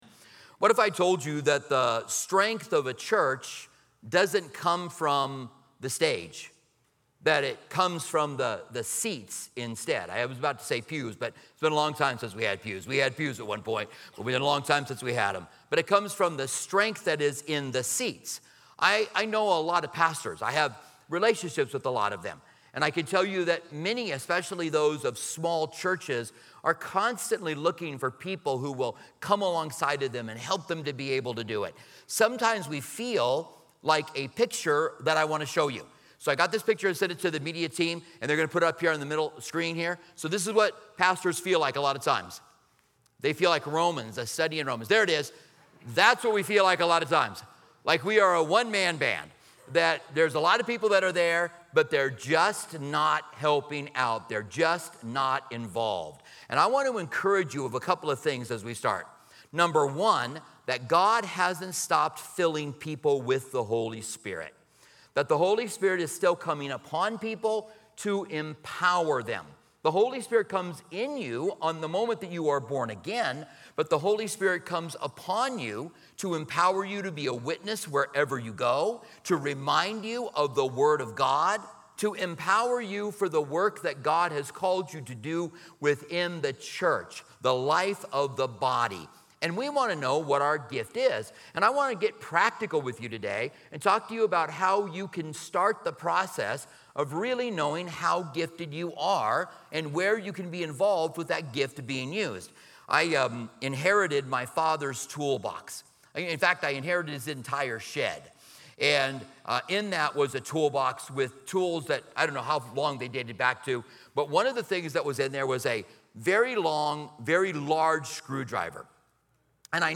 In this engaging sermon from Calvary's Online Campus, the discussion centers around the crucial role of spiritual gifts in fostering a vibrant church community, as explained in Romans 12:4-8. It emphasizes that just like a body has various parts with unique functions, every believer's distinctive gifts—such as prophecy, teaching, and leadership—contribute significantly to the strength and unity of the church.